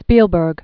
(spēlbûrg), Steven Born 1946.